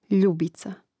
ljȕbica ljubica